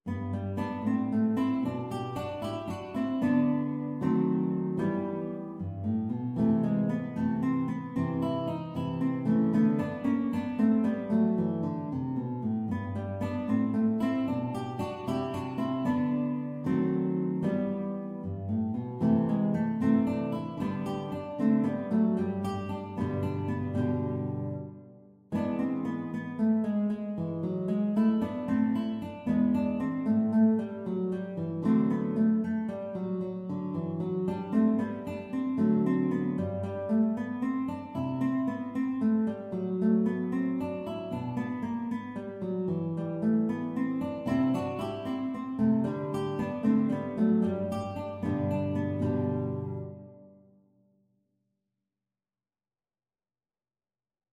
G major (Sounding Pitch) (View more G major Music for Guitar Duet )
Andante . = 76
12/8 (View more 12/8 Music)
Guitar Duet  (View more Intermediate Guitar Duet Music)
Classical (View more Classical Guitar Duet Music)